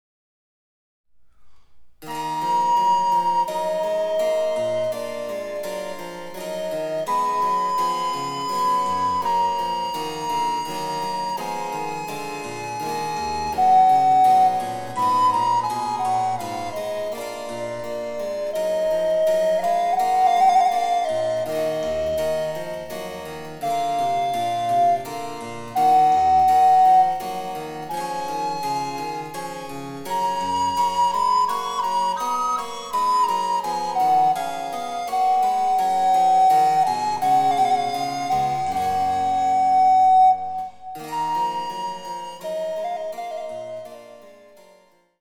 全体に晴朗ではれやかな感じの強い曲ですが、第３楽章は平行短調のホ短調で少し暗い表情をみせます。
■リコーダーによる演奏
バロックピッチによる）　　チェンバロ（電子楽器）